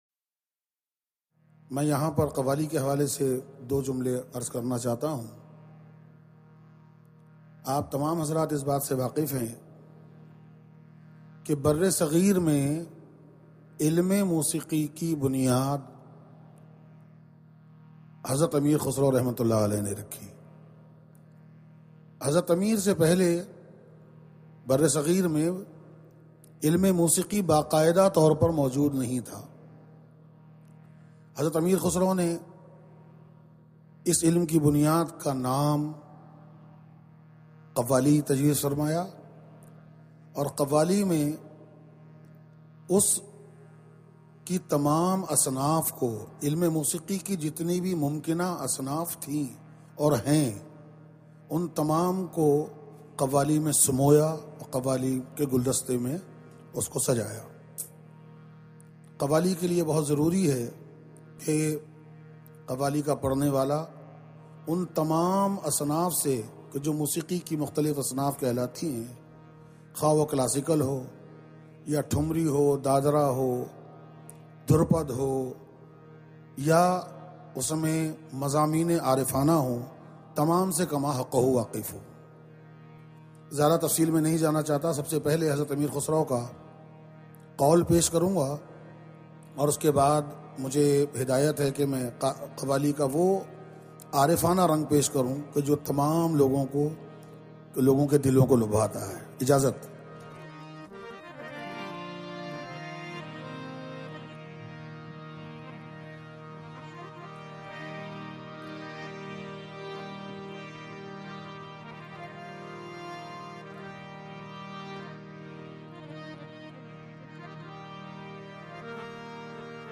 Sufi Music